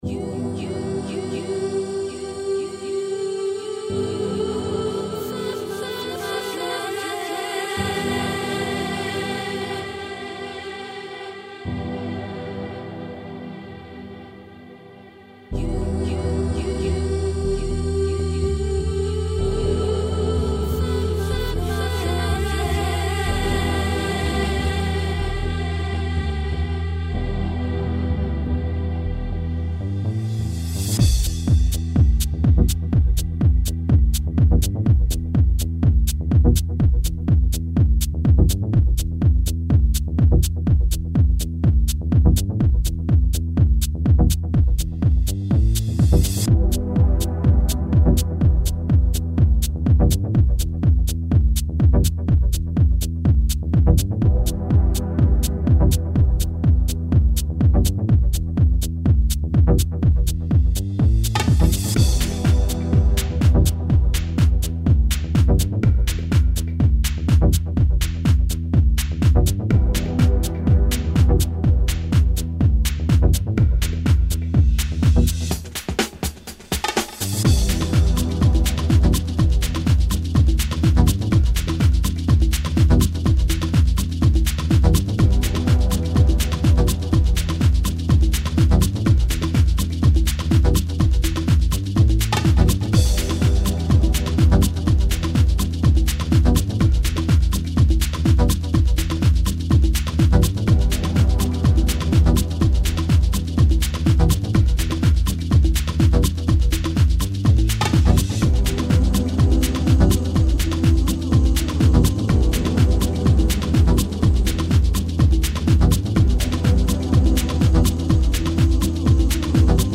Four on the floor remix version